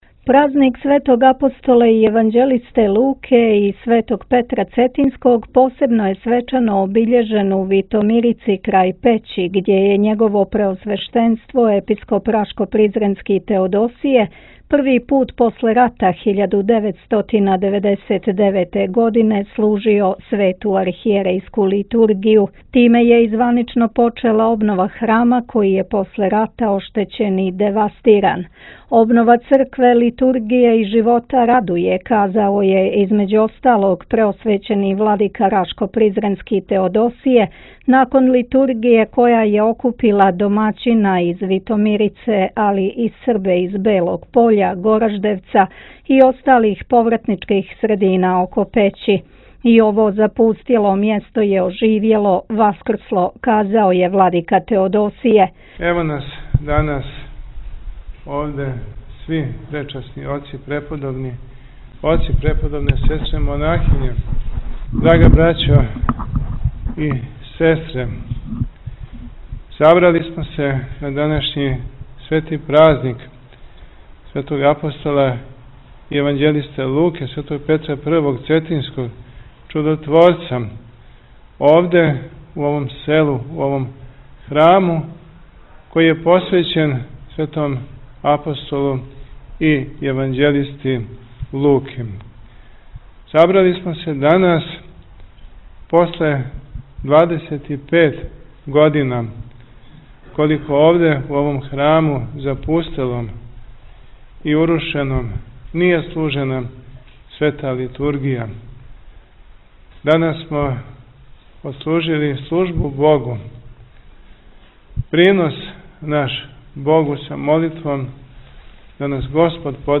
После 25 година на Лучиндан се служило Богу у храму Светог Луке у Витомирици код Пећи • Радио ~ Светигора ~
Празник Светог Апостола и јеванђелисте Луке посебно је свечано обиљежен у Витомицири крај Пећи гдје је Његово преосвештенство Владика рашко-призренски Теодосије први пут после рата 1999. године служио свету ахријерејску литургију. Тиме је и званично почела обнова храма који је после рата оштећен и девастиран.
Обнова цркве, литургије и живота радује, рекао је владика рашко призренски Теодосије након литургије која је окупила домаћина из Витомирице али и Србе из Белог Поља, Гораждевца и осталих повратничких средина око Пећи.